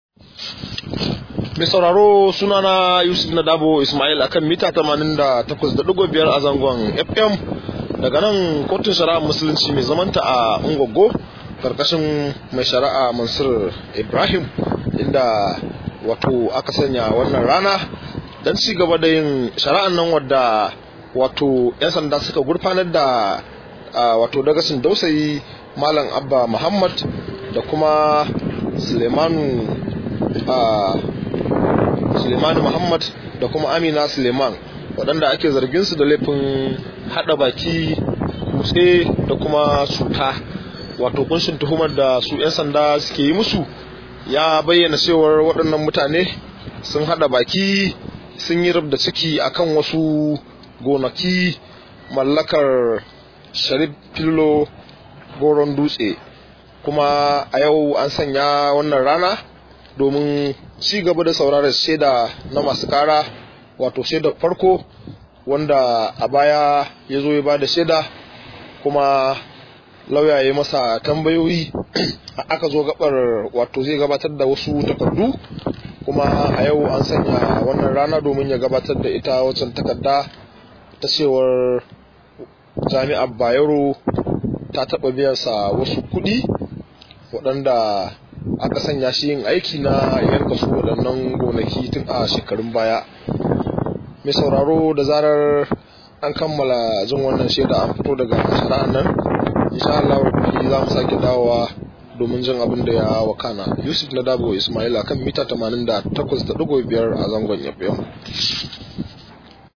Rahoto: Dagacin Dausayi ya sake gurfana a kotu da zargin cinye gonaki